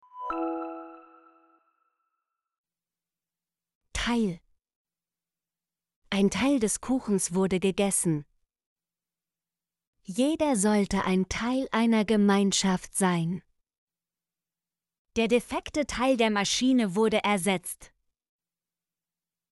teil - Example Sentences & Pronunciation, German Frequency List